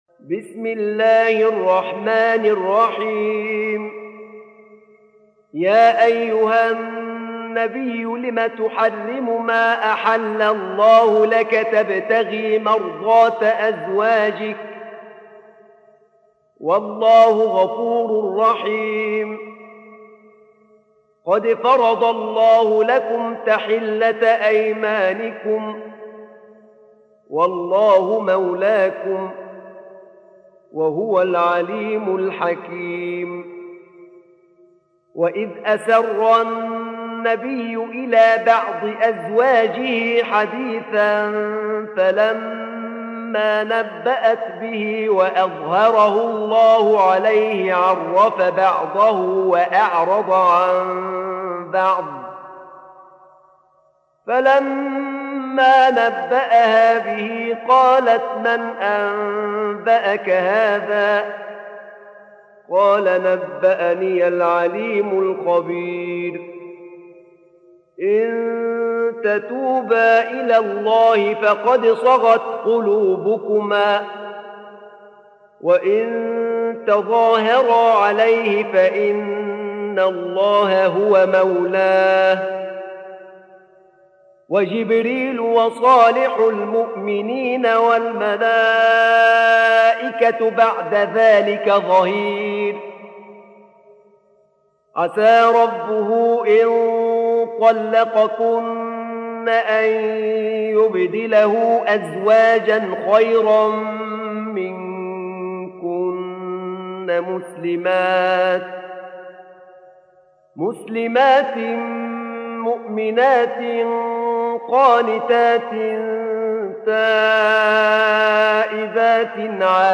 سورة التحريم | القارئ أحمد نعينع